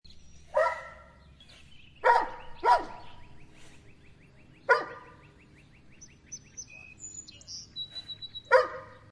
Dog Barks 01 Sound Button: Unblocked Meme Soundboard
Dog Barking Sound